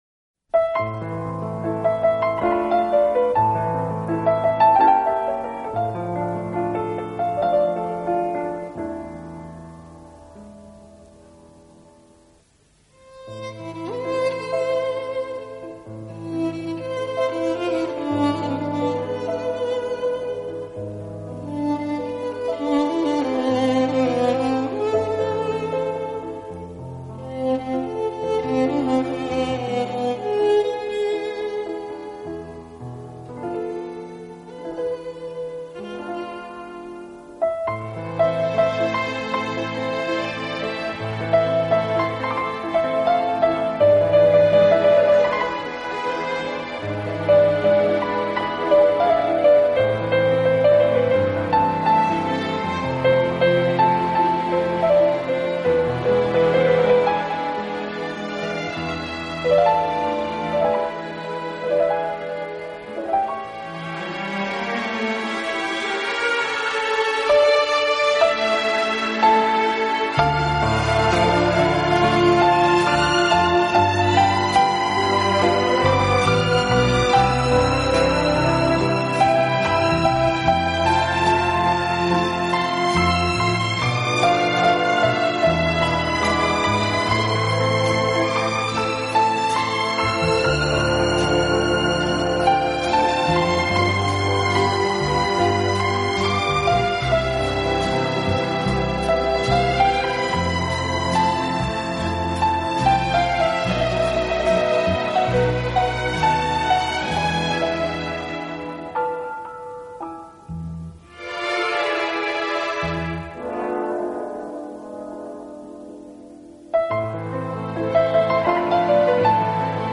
乐队以弦乐为中坚，演奏时音乐的处理细腻流畅，恰似一叶轻舟，随波荡